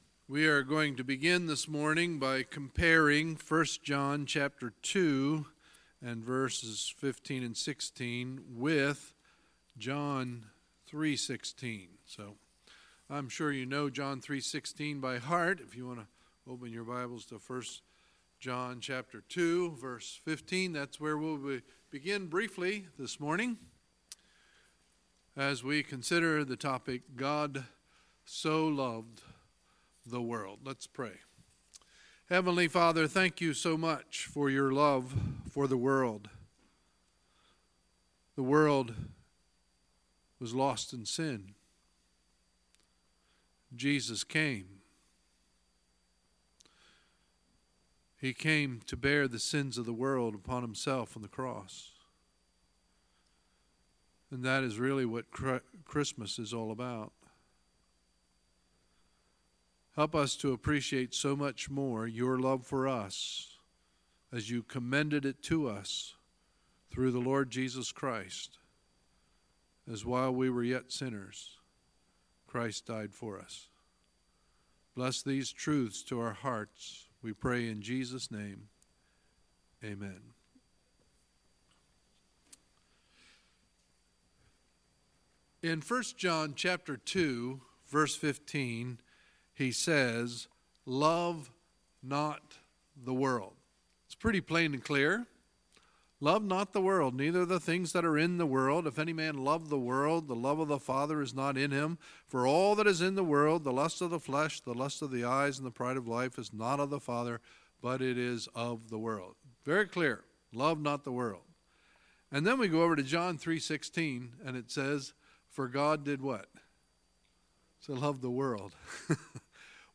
Sunday, December 2, 2018 – Sunday Morning Service